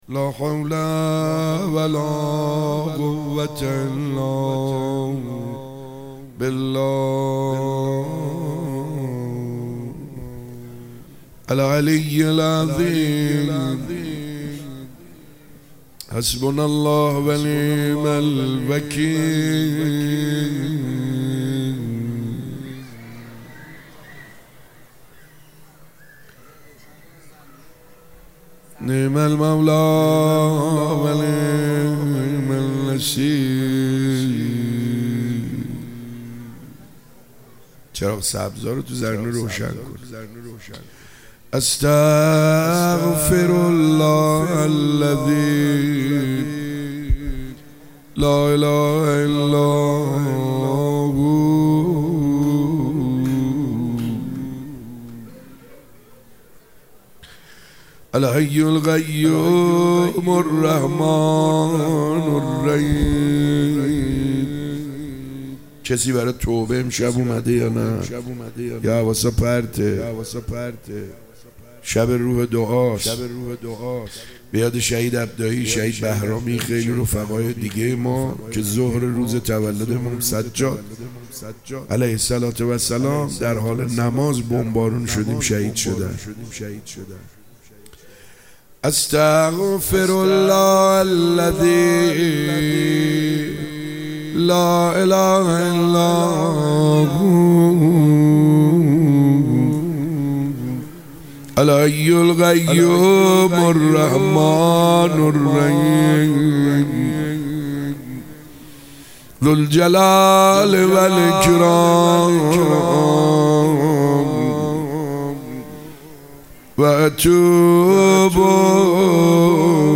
حاج سعید حدادیان؛ مناجات و روضه
ولادت امام سجاد(ع)